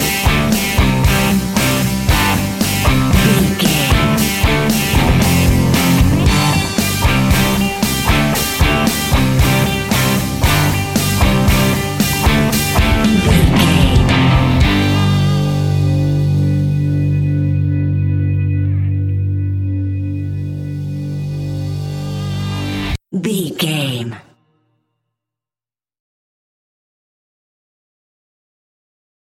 Epic / Action
Ionian/Major
hard rock
heavy rock
rock instrumentals
Rock Bass
Rock Drums
distorted guitars
hammond organ